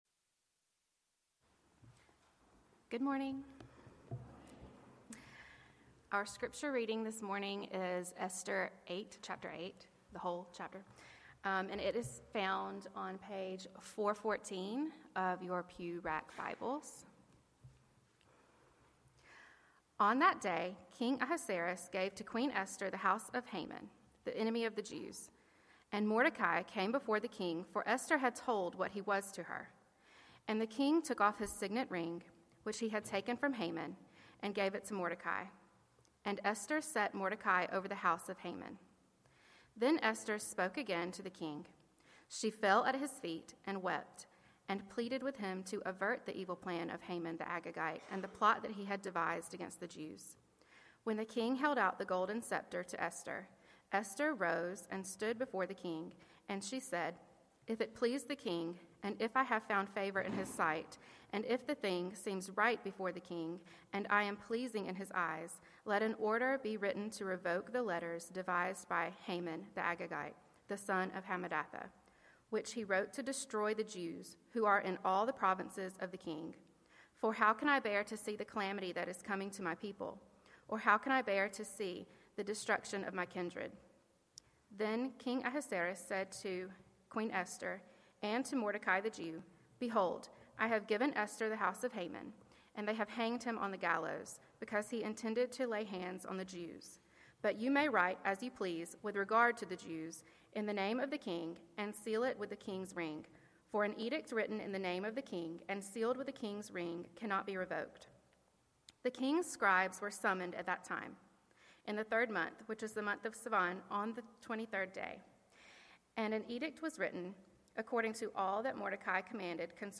Passage: Esther 8:1-17 Sermon